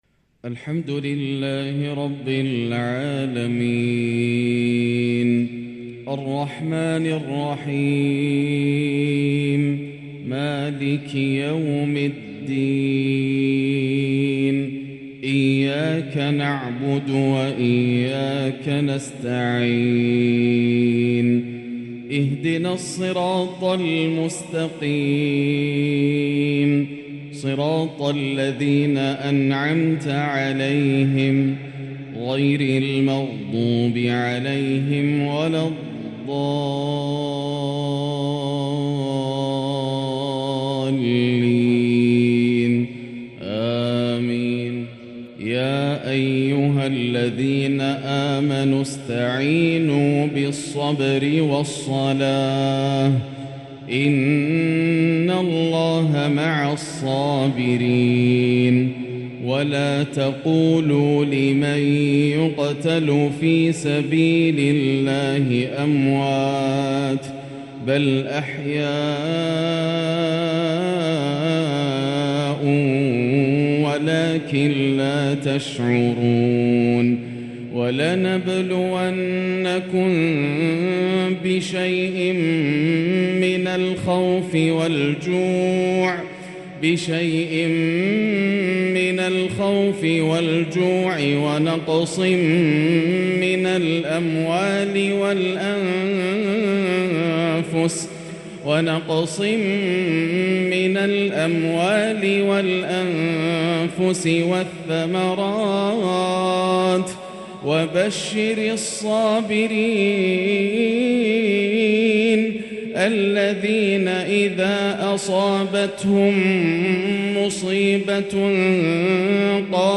“هذا ما وعد الرحمن” تحبير يلين له القلب ويبكي العين للآسر د.ياسر الدوسري > مقتطفات من روائع التلاوات > مزامير الفرقان > المزيد - تلاوات الحرمين